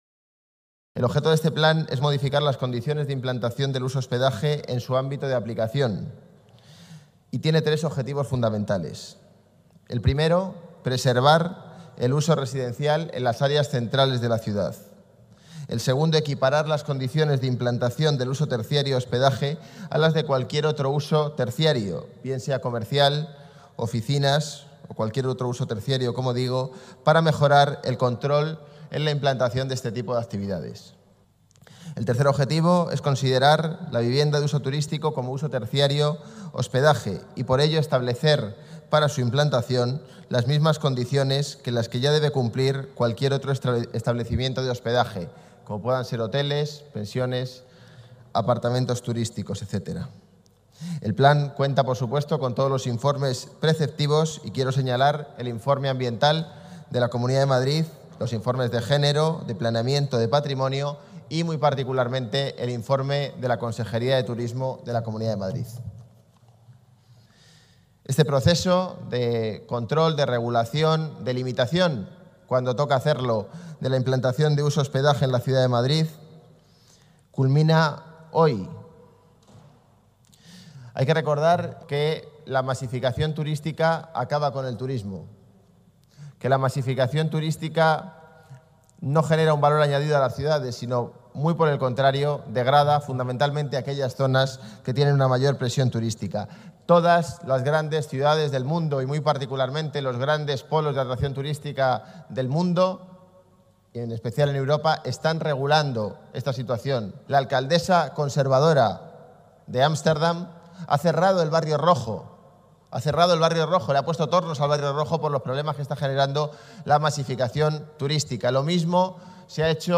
Nueva ventana:José Manuel Calvo, delegado de Desarrollo Urbano Sostenible, habla sobre el Plan Especial para la regulación de uso terciario en su clase de Hospedaje